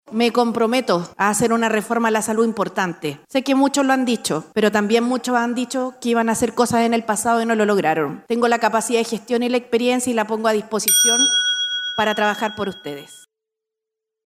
Sin mayores polémicas se desarrolló el panel presidencial en el Encuentro Nacional de la Empresa (Enade) 2025, en el que participaron siete de los ocho candidatos que buscan llegar a La Moneda.
Palabras finales de los candidatos
Le siguió la candidata oficialista, Jeannette Jara, señalando que le preocupa “el 80% de chilenos que están en Fonasa, que tiene que atenderse en salud pública y están en una crisis”.
214-cu-presidencial-jeannette-jara.mp3